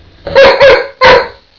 bark and growl.